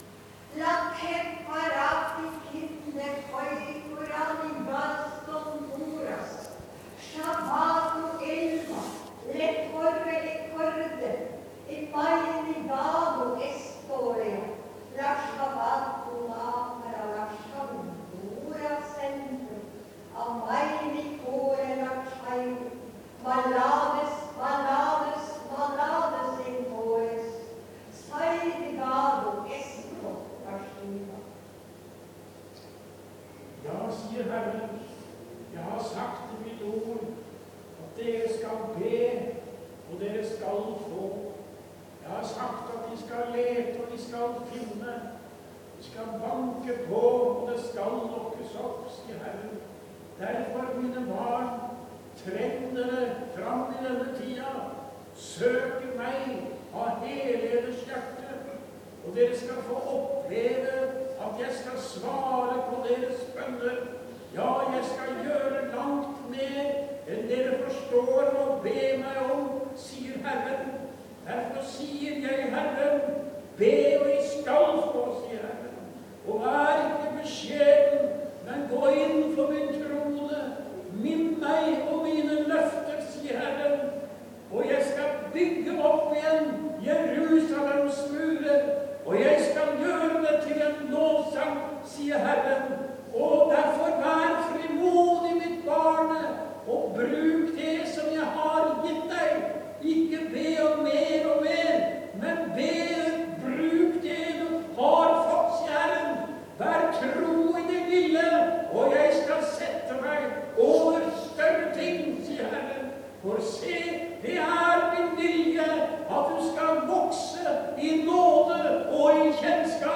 Nattverd, bønn og vitnemøte, Maranata 13.5.2012.
VITNEMØTE.